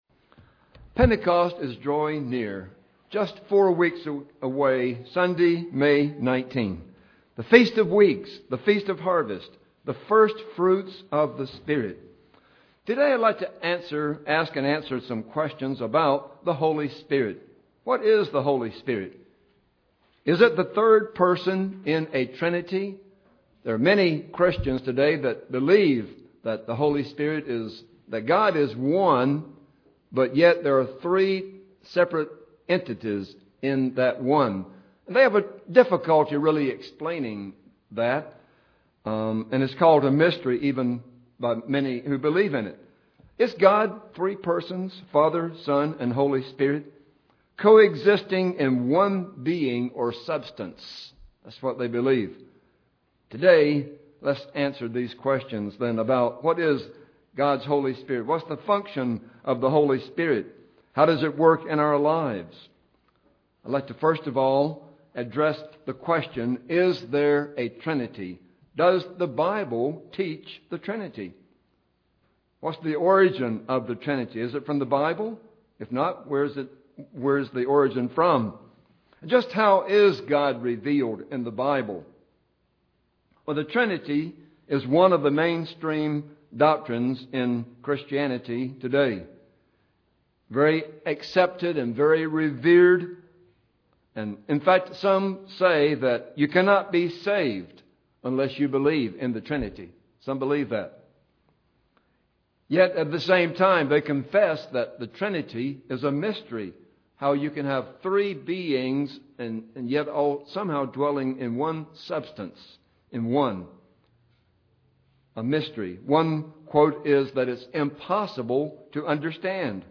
What is the Holy Spirit and how does it work in our lives? Listen to this sermon to find out the answers to these and other questions about the Holy Spirit.